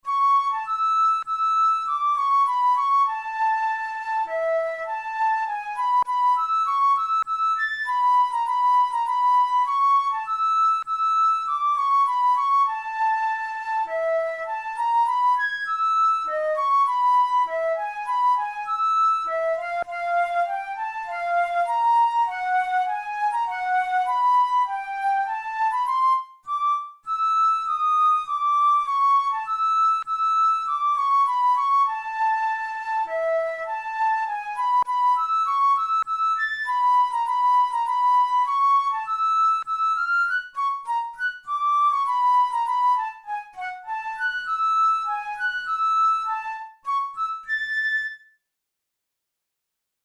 Categories: Etudes Romantic Written for Flute Difficulty: easy